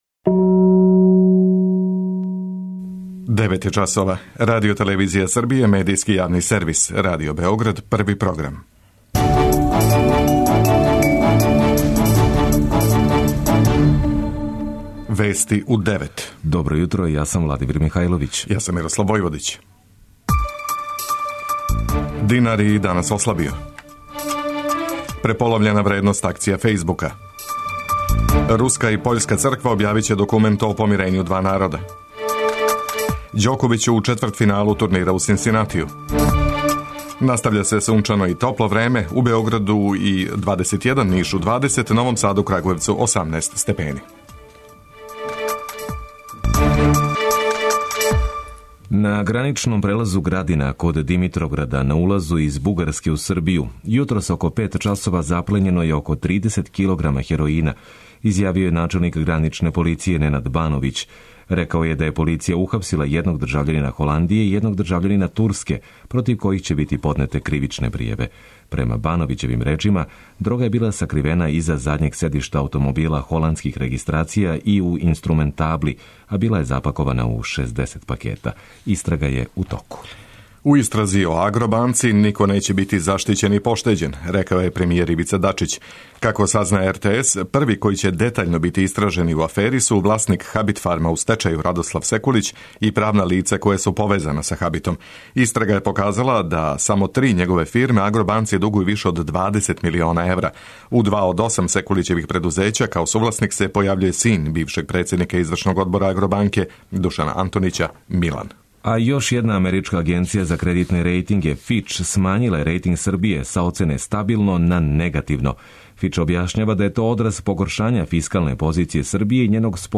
преузми : 10.01 MB Вести у 9 Autor: разни аутори Преглед најважнијиx информација из земље из света.